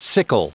Prononciation du mot sickle en anglais (fichier audio)
Prononciation du mot : sickle